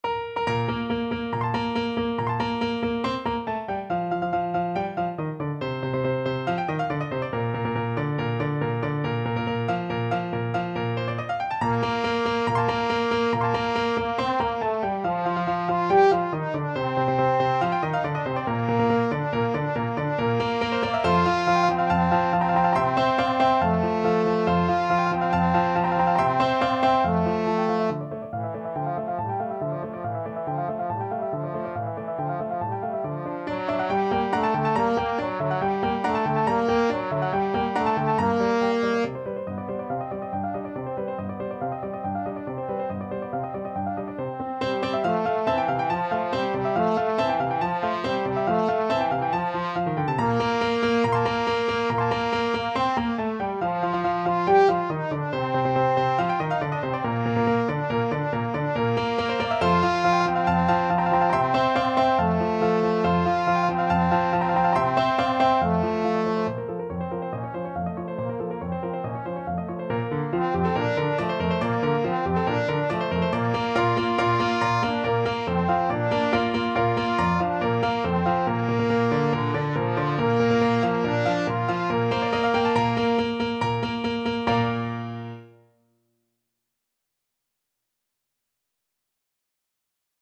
2/4 (View more 2/4 Music)
~ = 140 Allegro vivace (View more music marked Allegro)